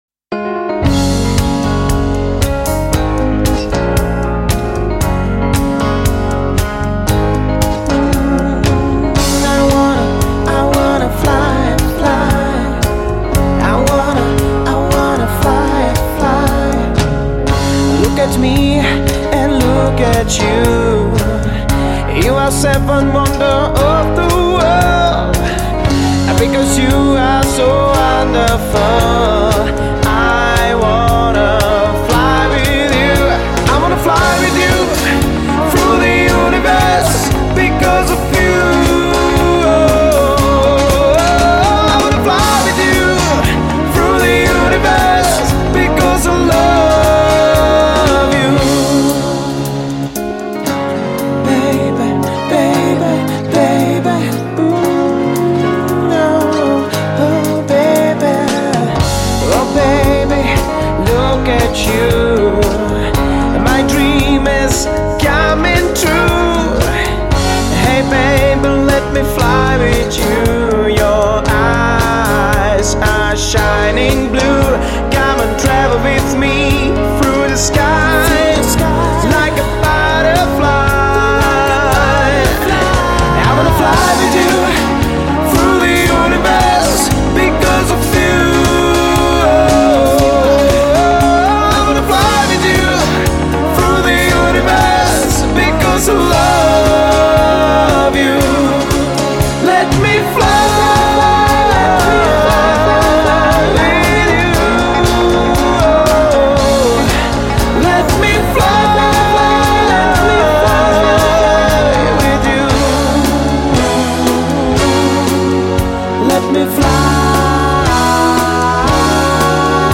Žánr: Pop
CD bylo nahráno ve známém ostravském studiu Citron.